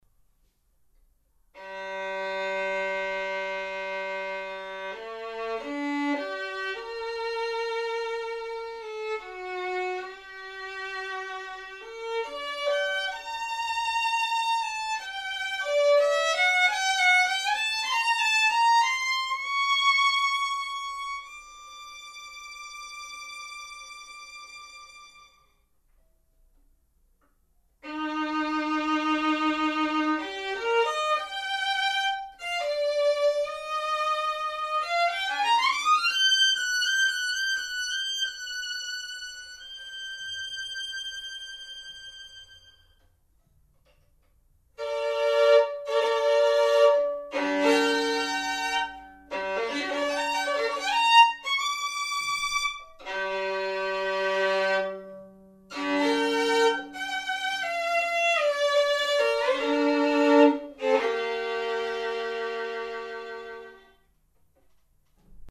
小提琴
• 搭配Evah Pirazzi 琴弦 Dispiau三星琴桥
*?请使用高品质耳机收听,作品录音无任何后期加工